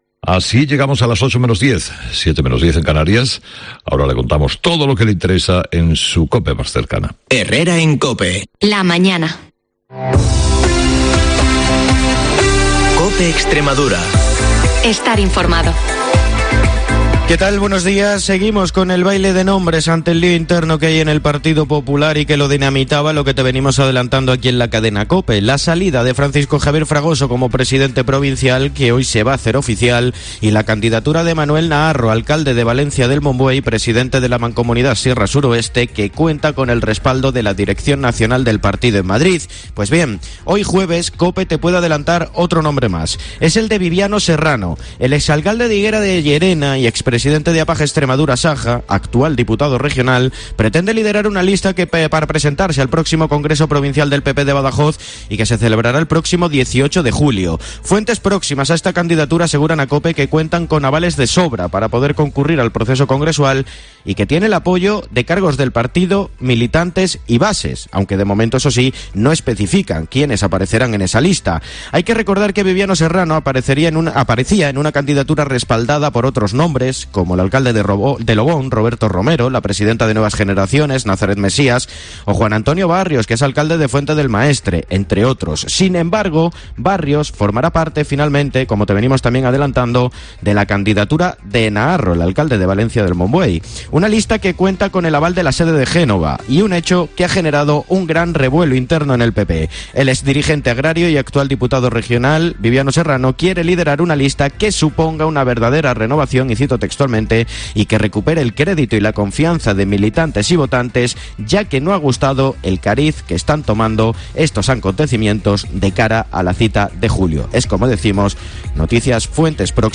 el informativo líder de la radio en la región